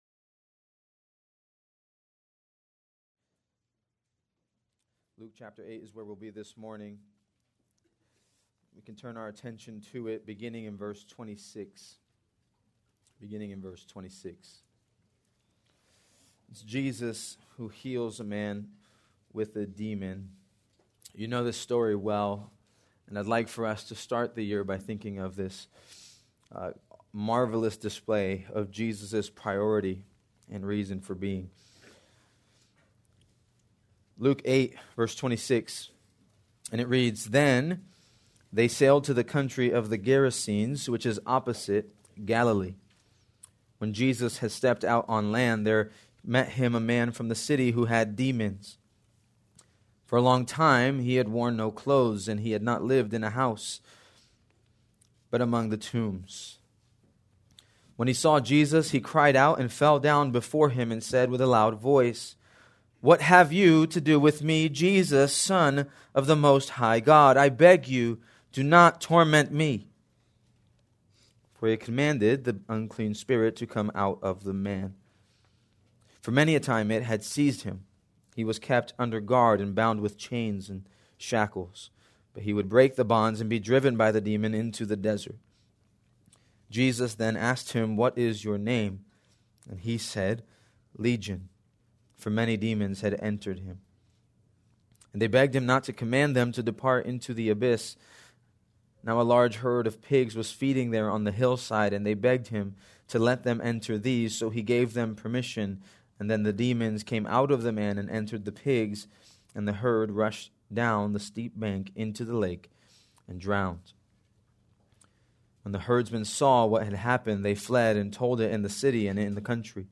January 5, 2025 -Sermon